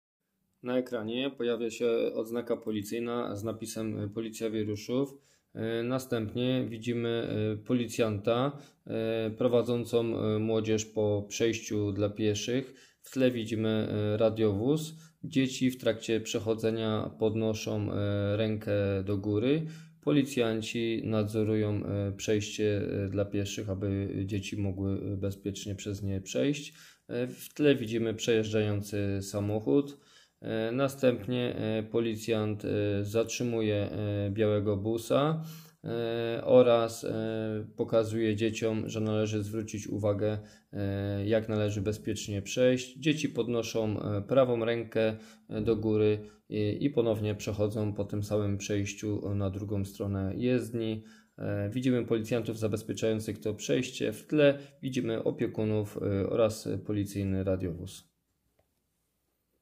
Nagranie audio audiodeskrypcja_droga.m4a